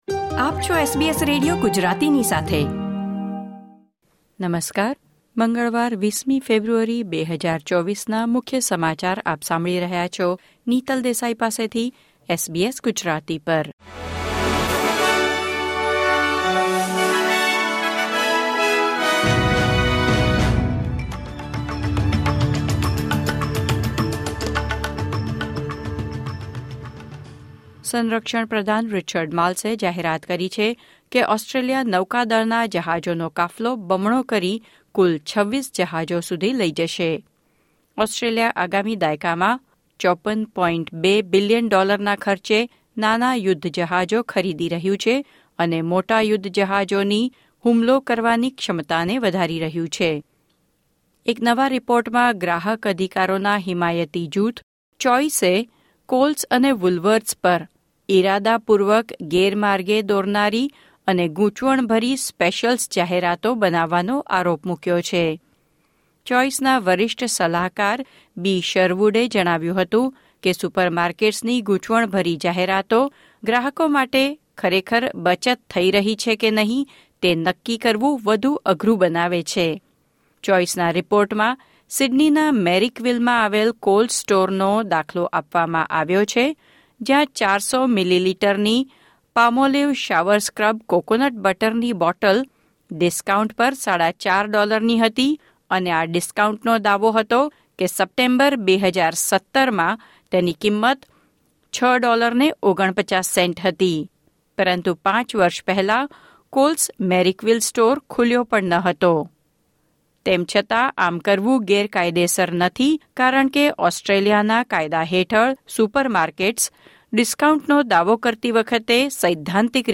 SBS Gujarati News Bulletin 20 February 2024